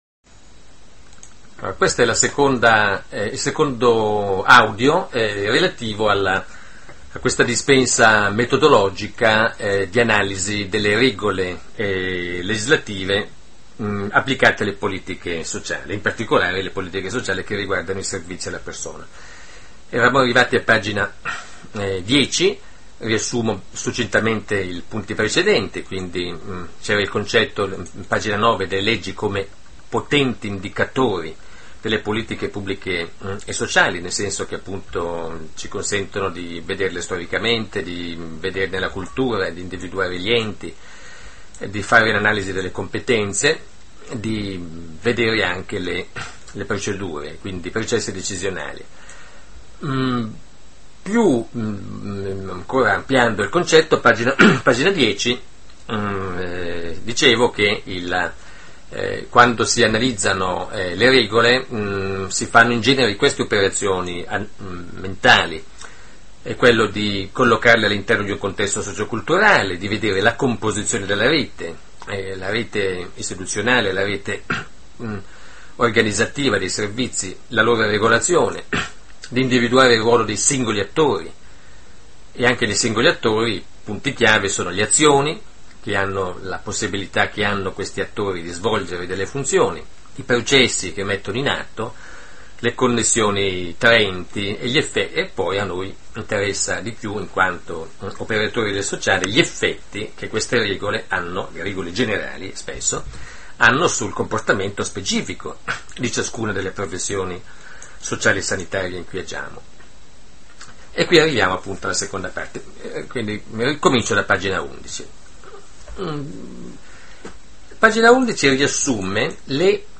Audiolezioni